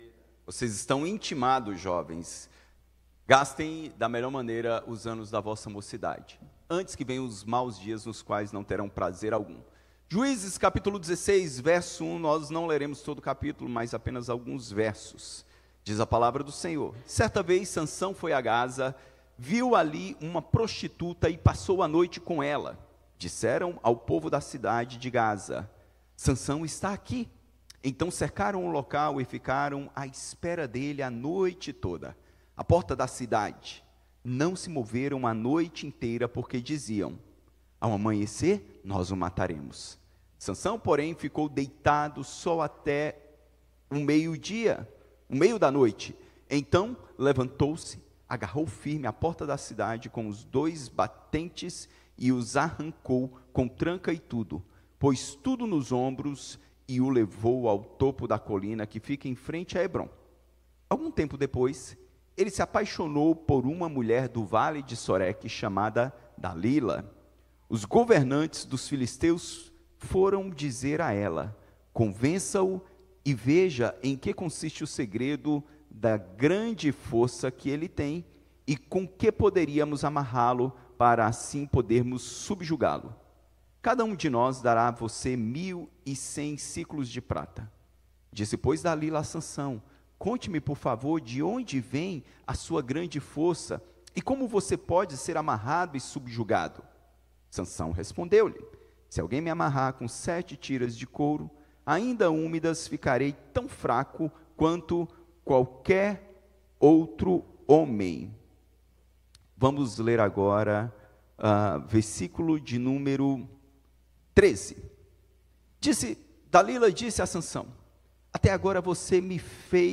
Sermons from Huntingdon Valley Presbyterian Church